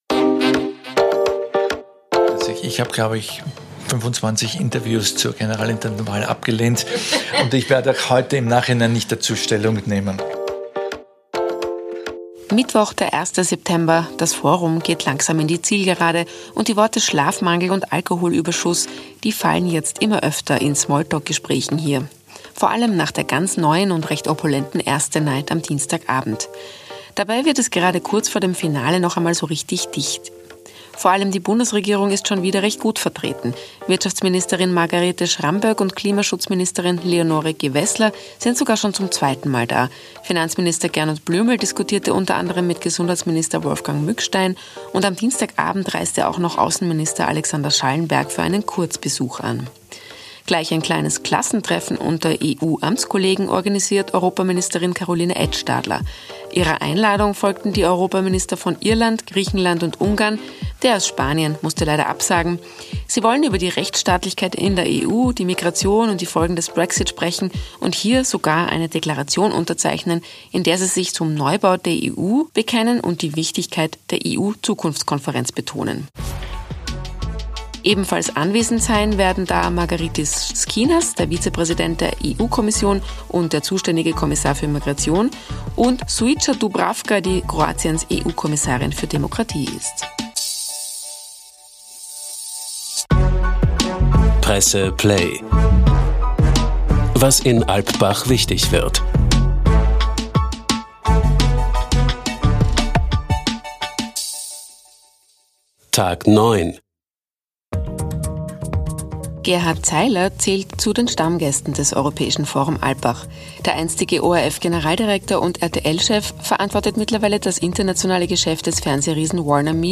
Er ist verantwortlich für das internationale Fernsehgeschäft von Warner Media. Im Gespräch erzählt Gerhard Zeiler, was der Konzern in Sachen Streaming zum Beispiel mit HBOMax plant, spricht über den ORF und den Zustand der Sozialdemokratie.